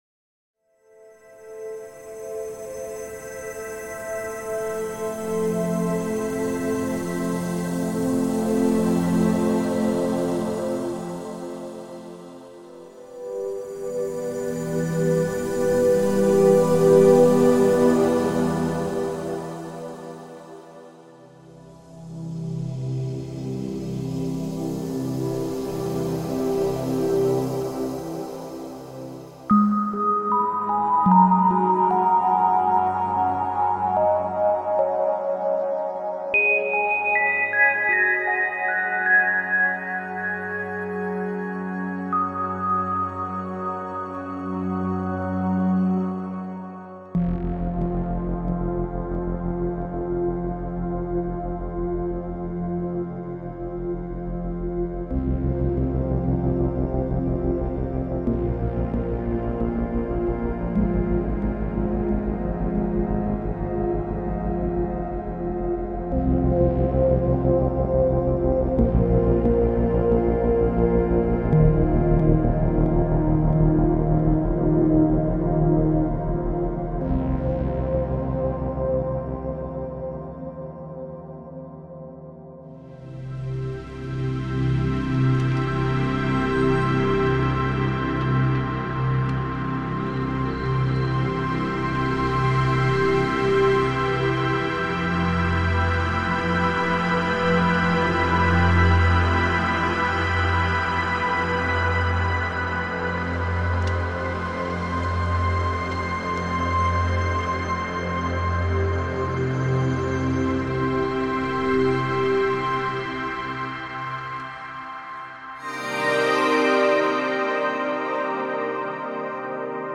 LUSH HARMONIC TEXTURES
PADS, PADS, AND MORE PADS
クラシックなアナログからアバンギャルドなアンビエント、シネマティックなアンダースコアから躍動感のあるダンス音楽まで、Lunarisは豊富な種類のパッド、リード、サウンドスケープ、ドローンを収録。
Lunaris_pads_1.mp3